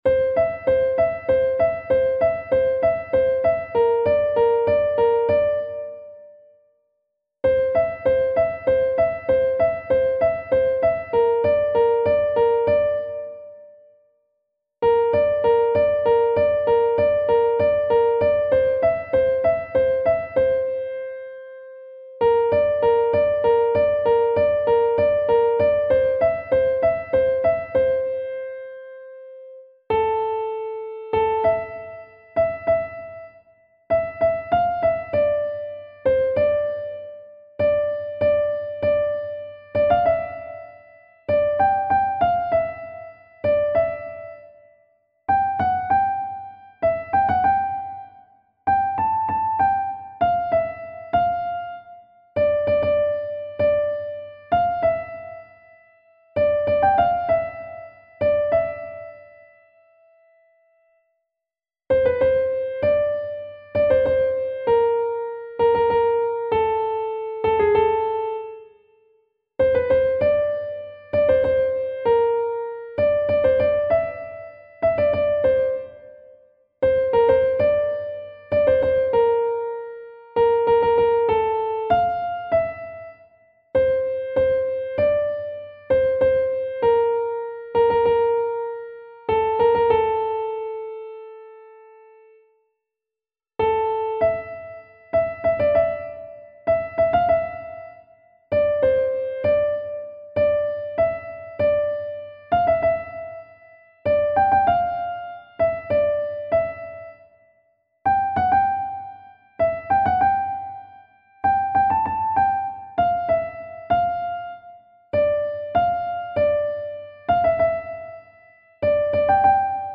دسته : سنتی ایرانی